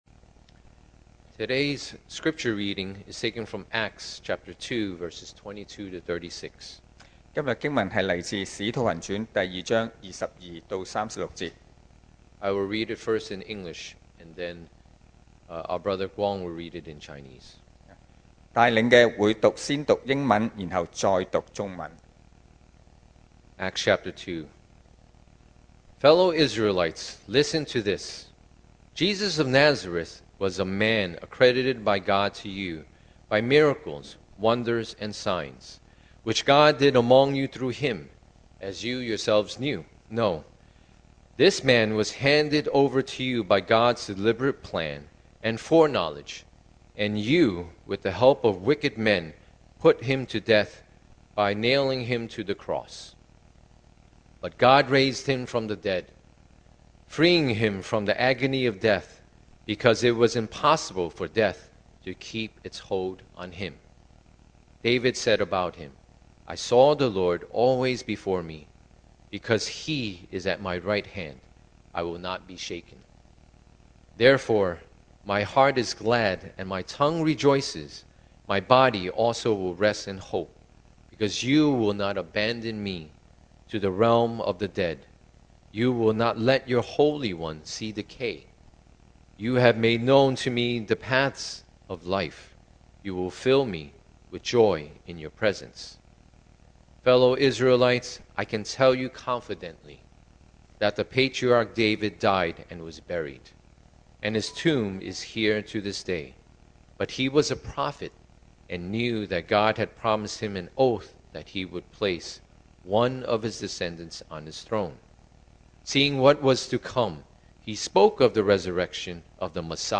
2025 sermon audios
Service Type: Sunday Morning